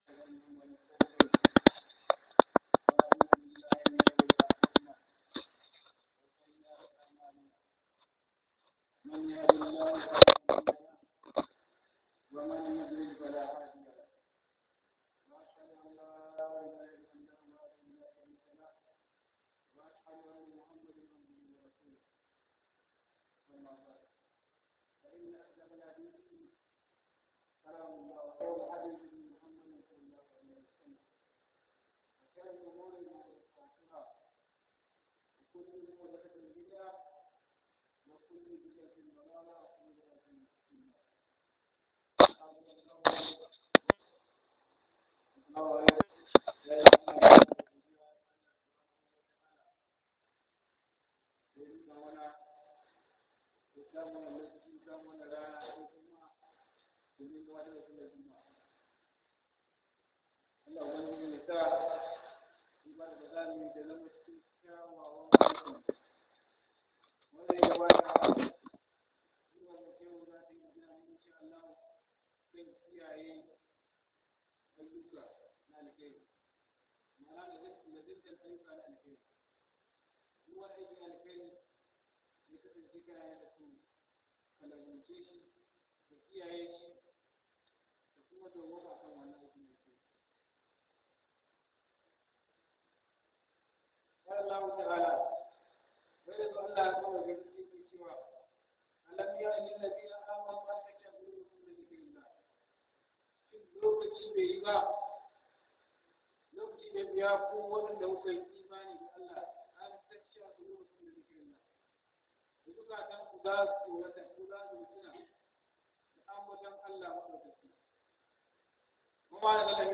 Khuduba